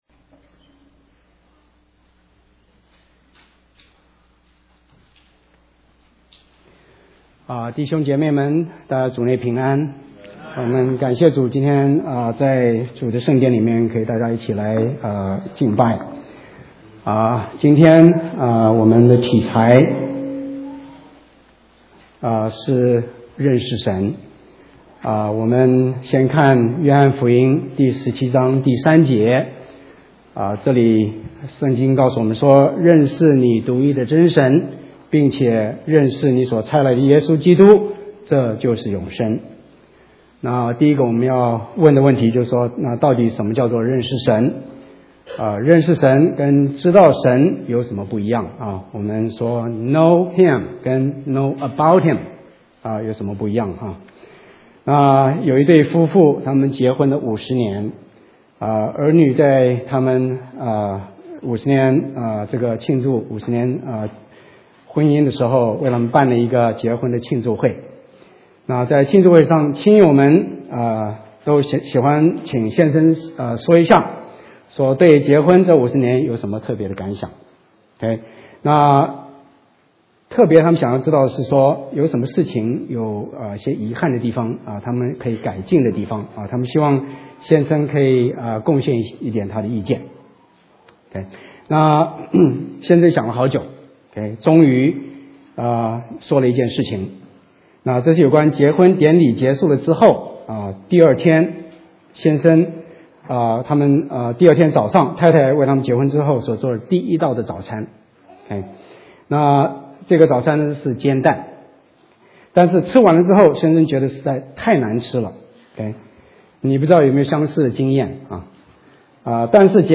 成人主日学－2011春季课程专题讲座录音 ––基督徒的金钱与理财观 (II)